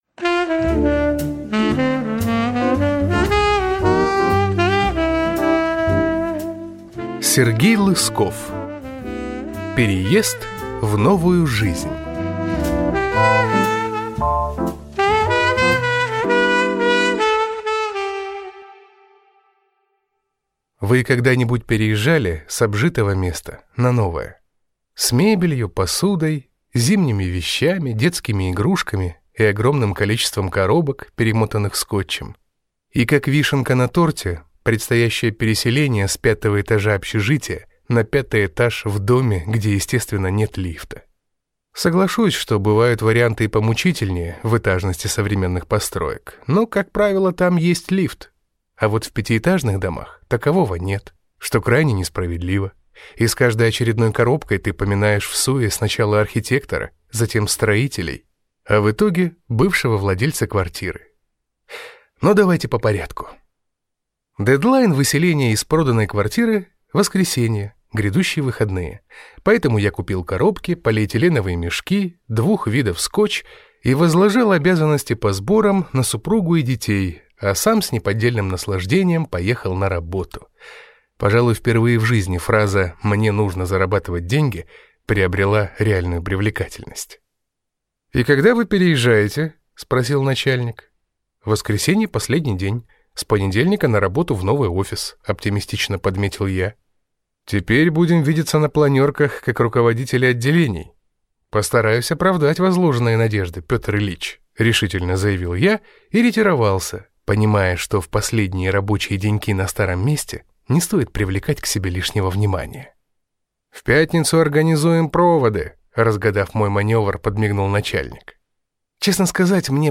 Аудиокнига Переезд в новую жизнь | Библиотека аудиокниг
Прослушать и бесплатно скачать фрагмент аудиокниги